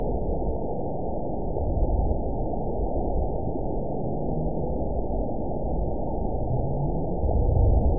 event 914036 date 04/26/22 time 04:16:32 GMT (3 years ago) score 9.04 location TSS-AB02 detected by nrw target species NRW annotations +NRW Spectrogram: Frequency (kHz) vs. Time (s) audio not available .wav